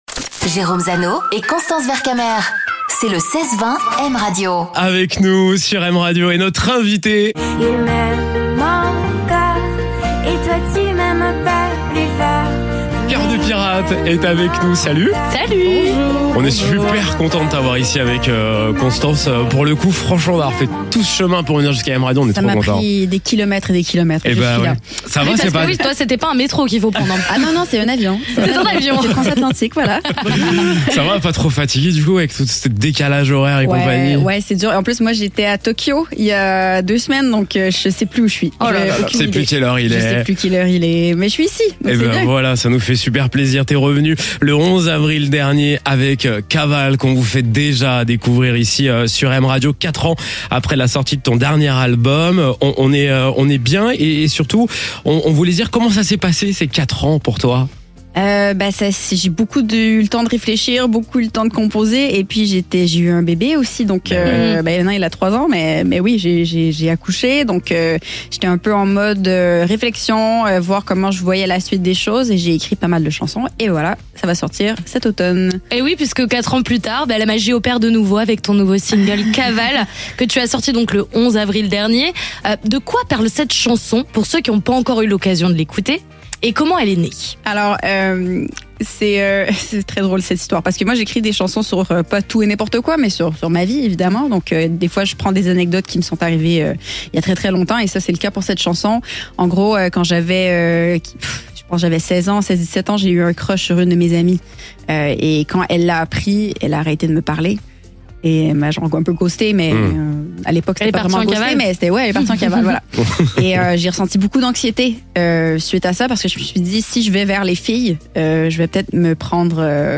La tournée fera escale en Europe au début de l'année 2026 Elle se confie sur ses 4 dernières années et nous parle de ses futurs projets au micro de M Radio Télécharger le podcast Partager :